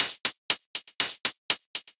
Index of /musicradar/ultimate-hihat-samples/120bpm
UHH_ElectroHatC_120-01.wav